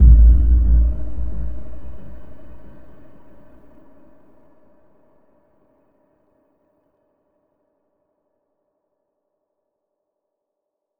Index of /musicradar/impact-samples/Low End
Low End 06.wav